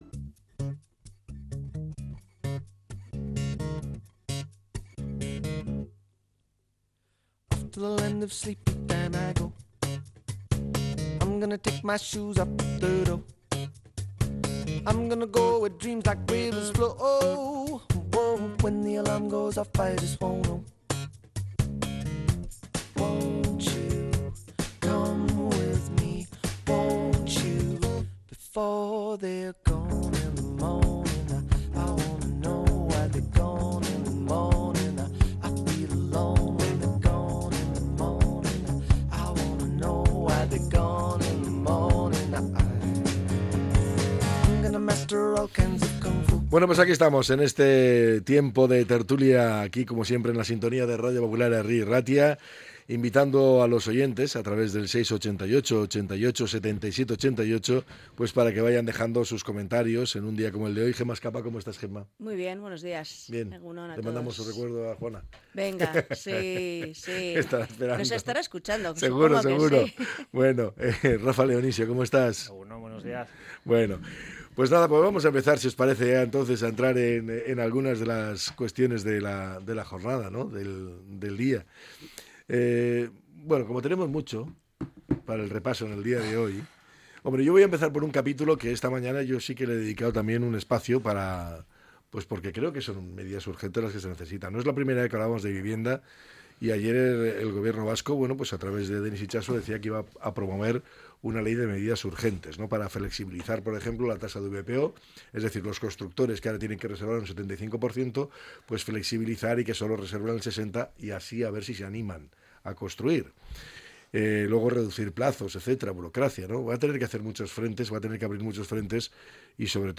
La tertulia 06-03-25.